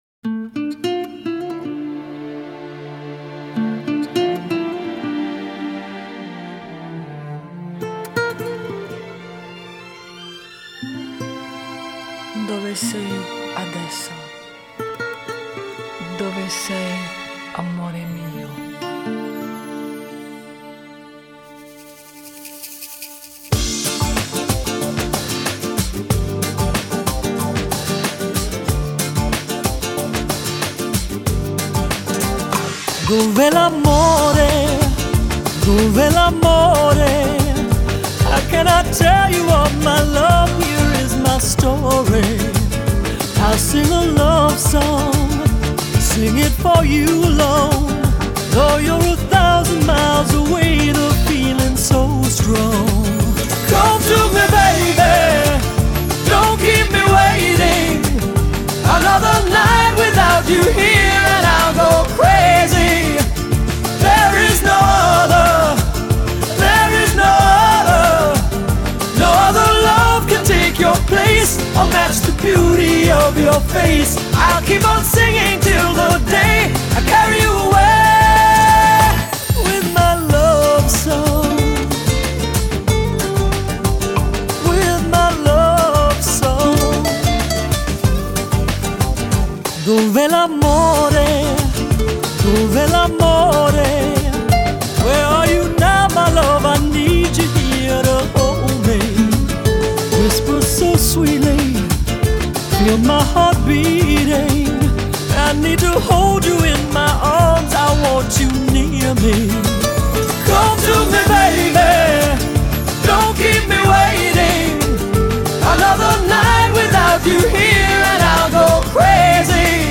پاپ خارجی آهنگ عاشقانه